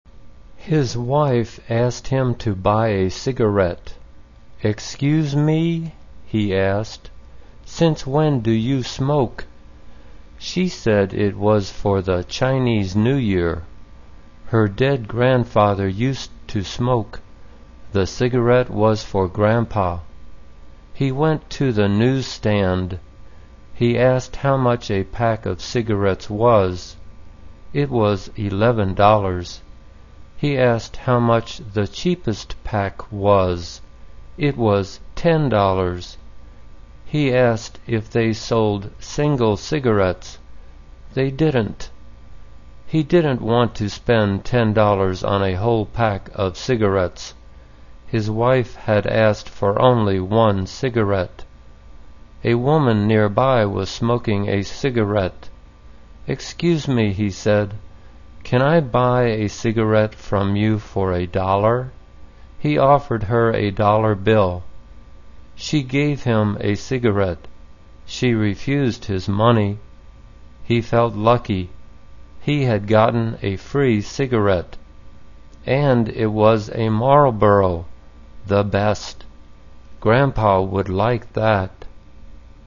简单慢速英语阅读：A Cigarette for Grandpa 听力文件下载—在线英语听力室